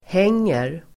Uttal: [h'eng:er]